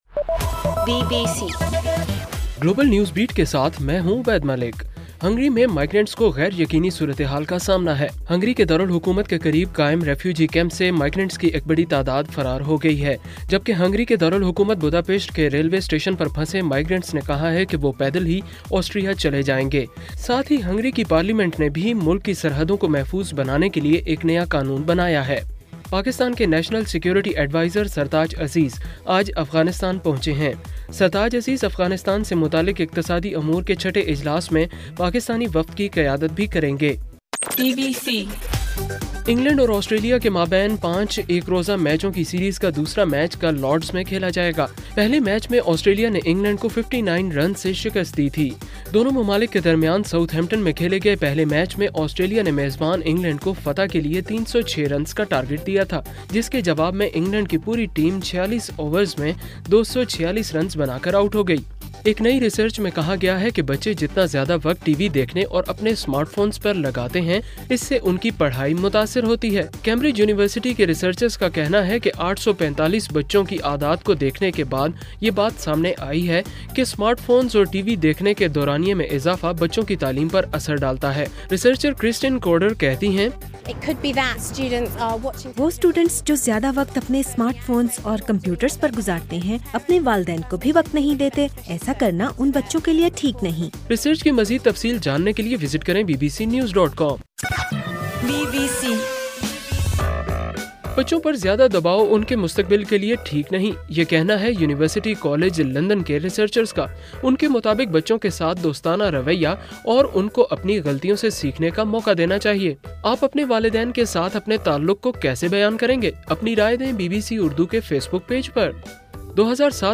ستمبر 4: رات 8 بجے کا گلوبل نیوز بیٹ بُلیٹن